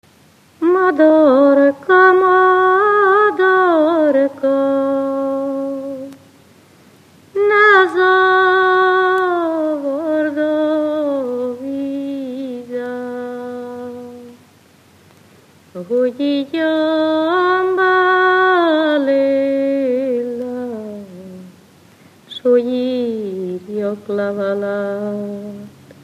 Moldva és Bukovina - Moldva - Lészped
ének
Műfaj: Ballada
Stílus: 3. Pszalmodizáló stílusú dallamok
Kadencia: 4 (b3) 1 1